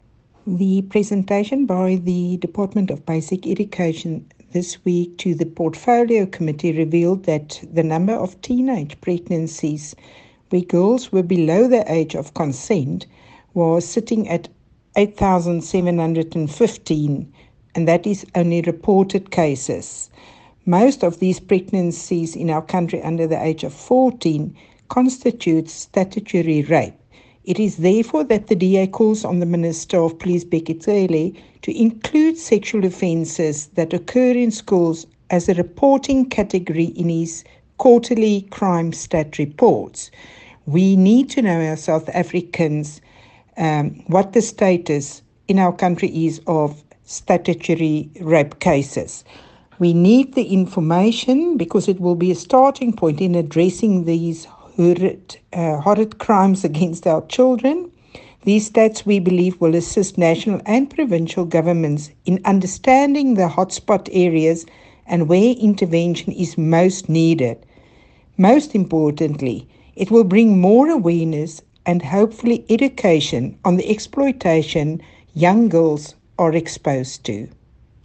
Please find attached soundbite in